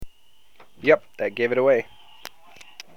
今日の発音
[発音]